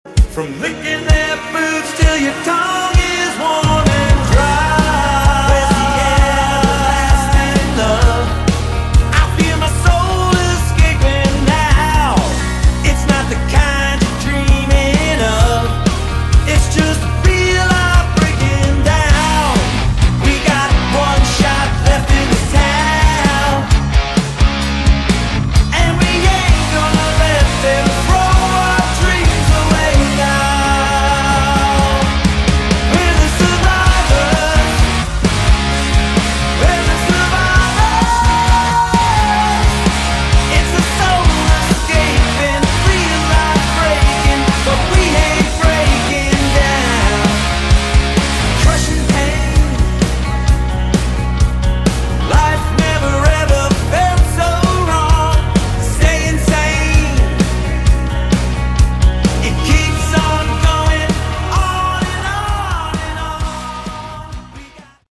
Category: Hard Rock
lead and backing vocals
guitar, keys
bass, backing vocals